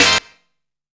TONE HIT.wav